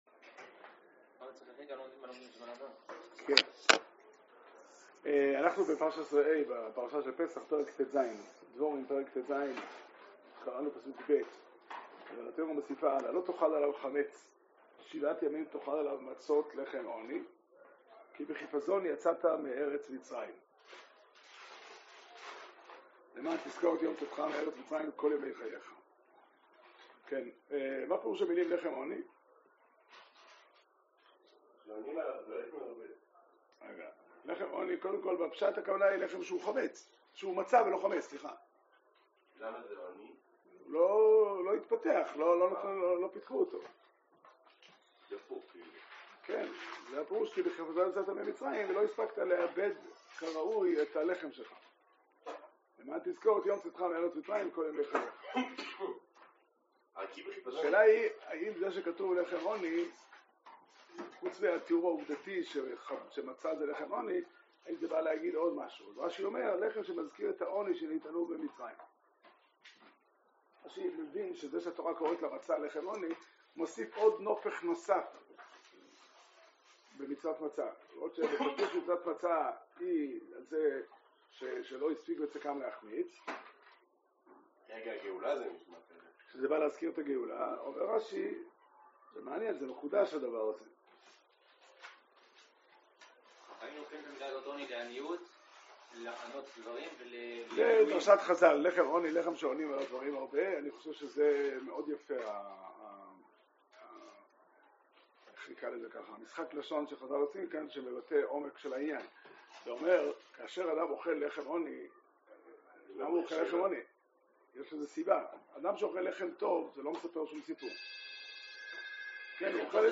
שיעור שנמסר בבית המדרש פתחי עולם בתאריך כ״ח באדר תשפ״ג